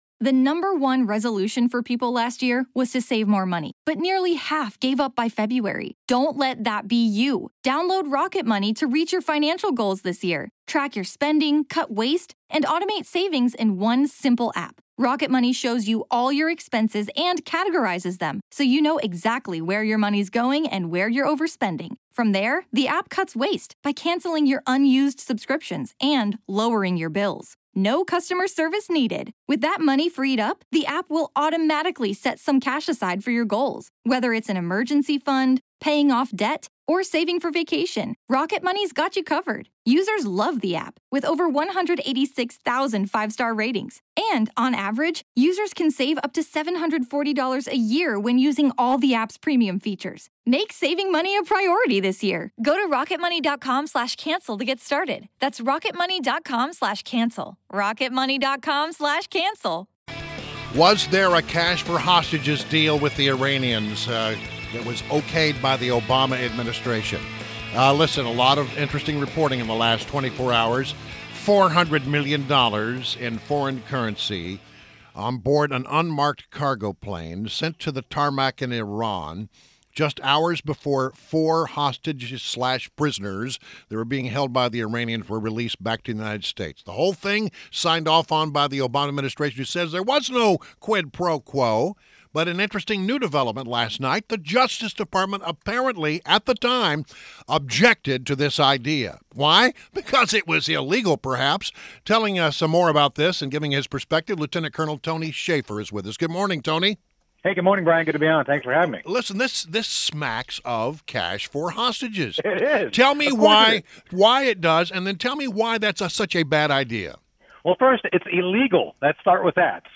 WMAL Interview - Lt. Col. Tony Shaffer - 08.04.16